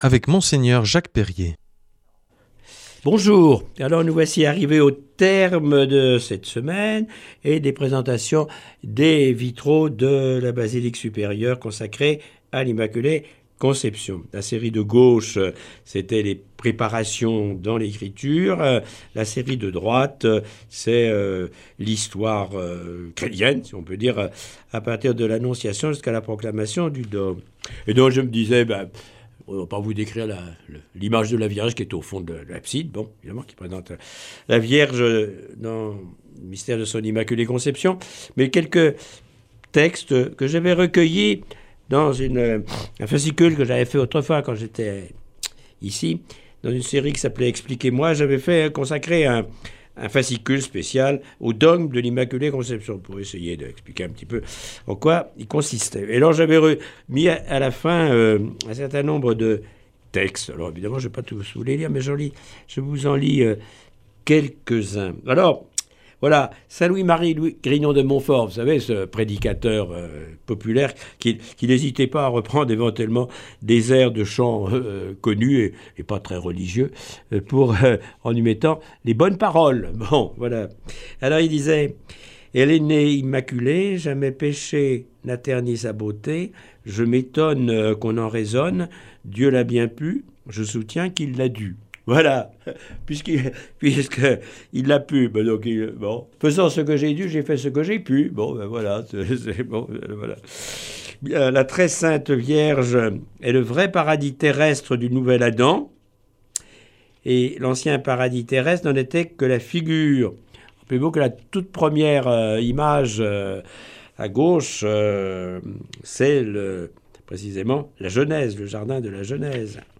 Mgr Jacques Perrier nous propose aujourd’hui quelques textes sur l’immaculée conception de la Vierge Marie.